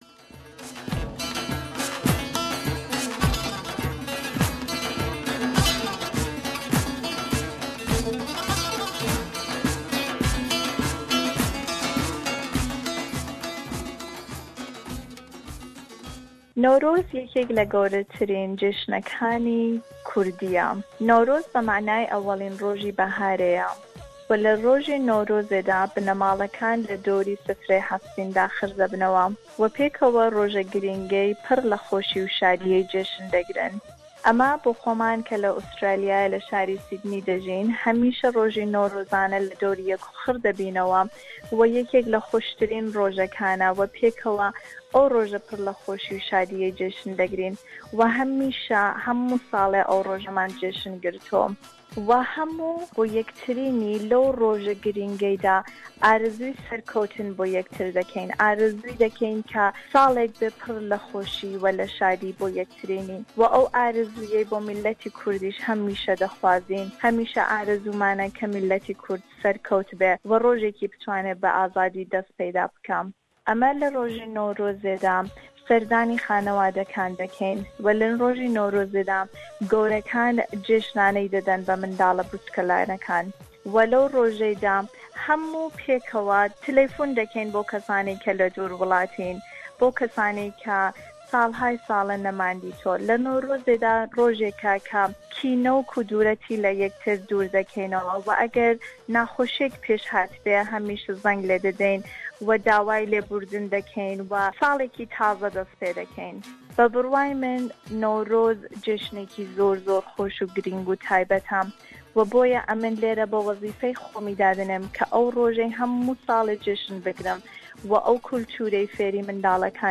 Our listeners from all over Australia (Sydney,Melbourne,Adelaide,Canberra,Brisbane and Perth) express their feelings about Newroz and what it means to them.